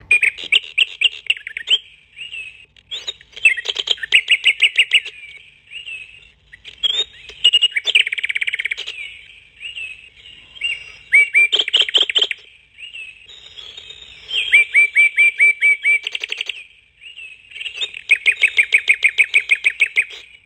From barking dogs to meowing cats and chirping birds, each clock has its own unique voice.